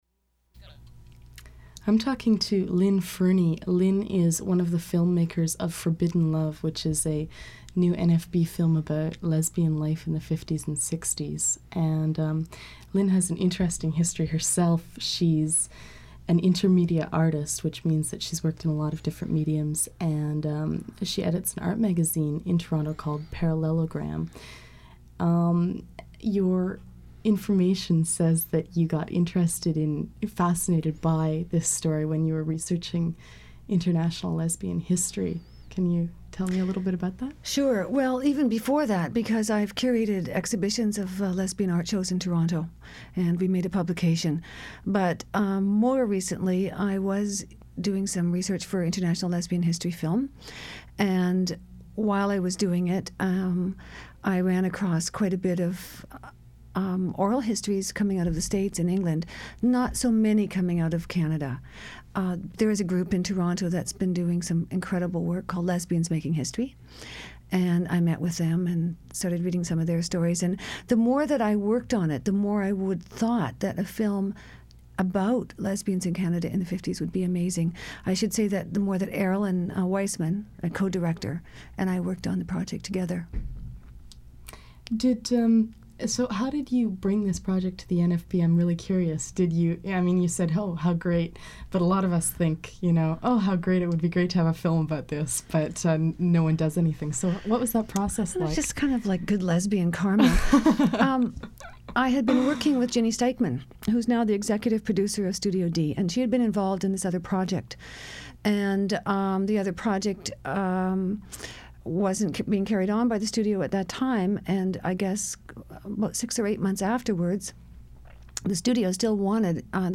Note: the recording skips throughout.
The Dykes on Mykes radio show was established in 1987.